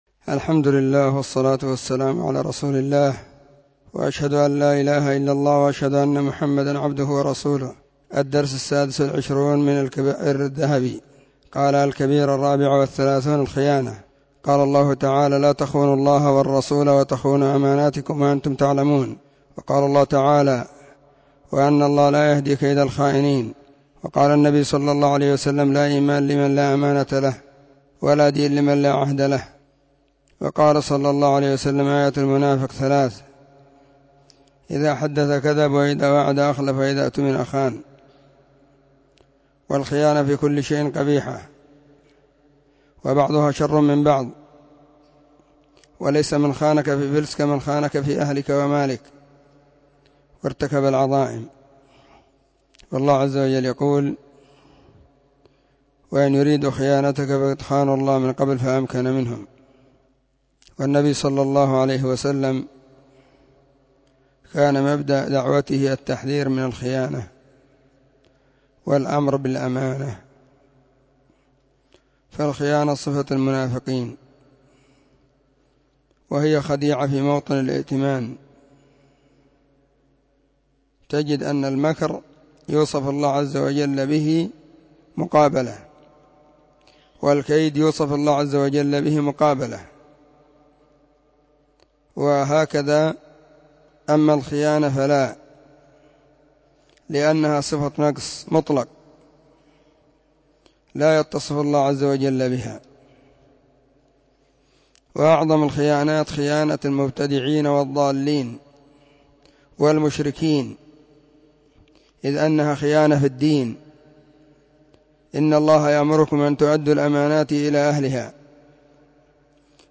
🕐 [بين مغرب وعشاء – الدرس الثاني]
كتاب-الكبائر-الدرس-26.mp3